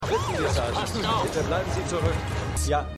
Krankenhausarzt
Deutsche Bearbeitung: Münchner Synchron